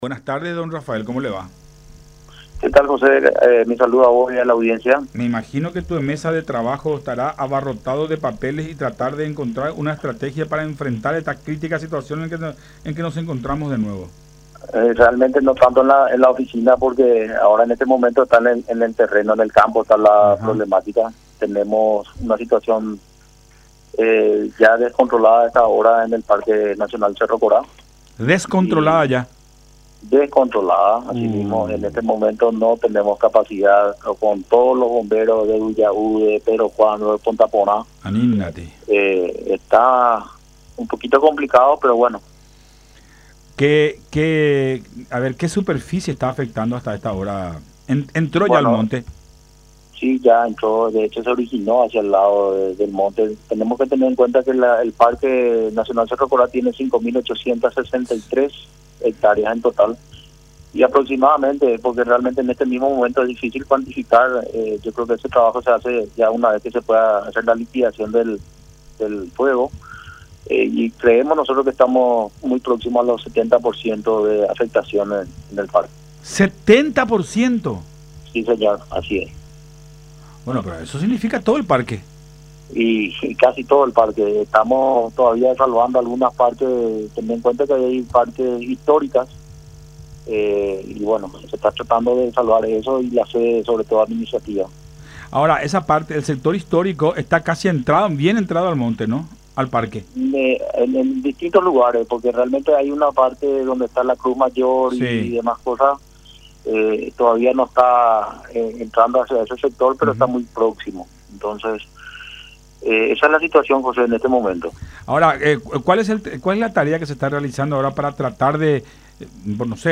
“Tenemos la situación descontrolada en la zona del Parque Nacional Cerro Corá. No alcanza con la cantidad de gente que está trabajando a estas horas y la afectación sería ya de un 70% del Parque. Se está tratando de salvar esa parte que queda y la parte administrativa”, informó Rafael Sosa, director general de Biodiversidad del Ministerio del Ambiente, en conversación con Buenas Tardes La Unión.